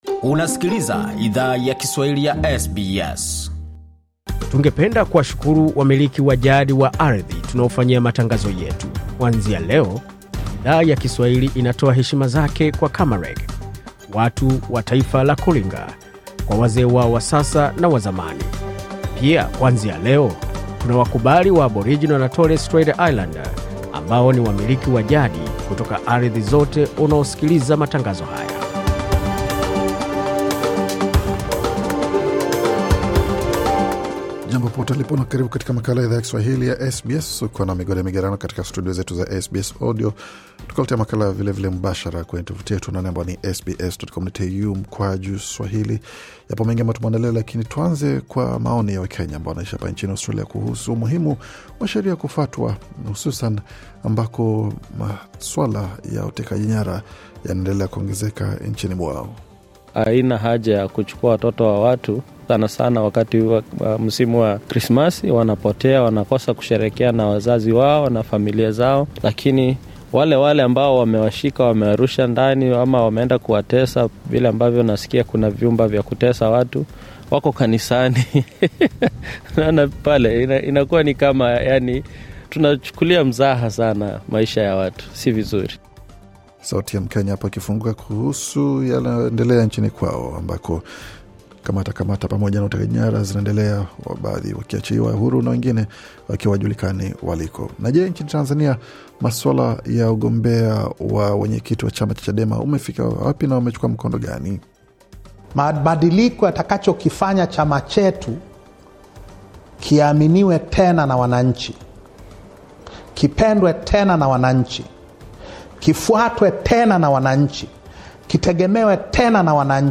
Taarifa ya Habari 10 Januari 2025